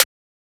pluggnbsnare1.wav